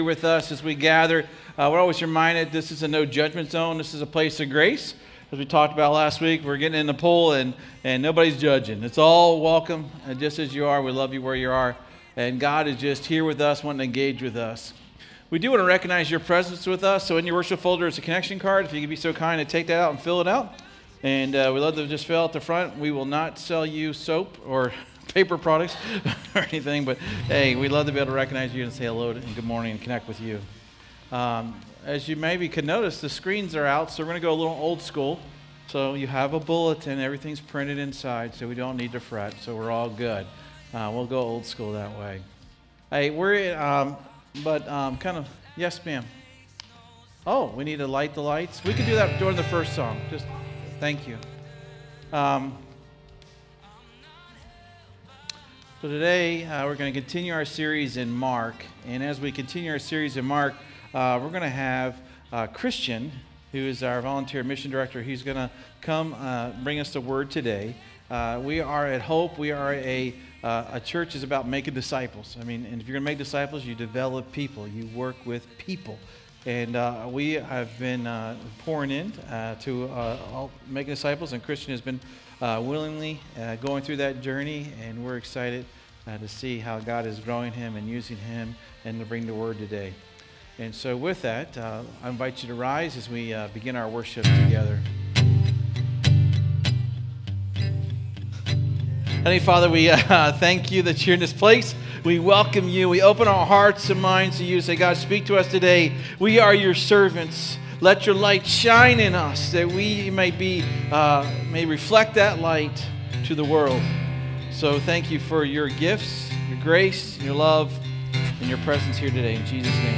SERMON DESCRIPTION God’s truth is not meant to be hidden—it is meant to shine.